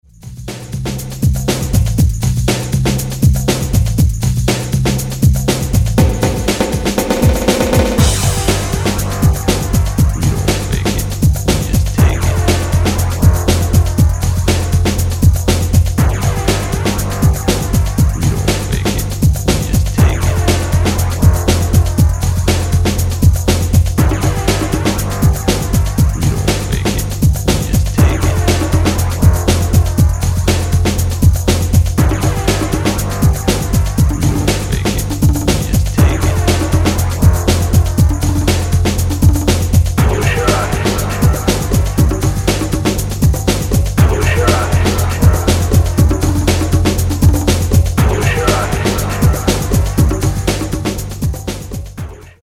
straighter nyc hardcore